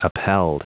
Prononciation du mot upheld en anglais (fichier audio)
Prononciation du mot : upheld